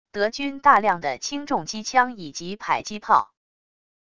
德军大量的轻重机枪以及迫击炮wav音频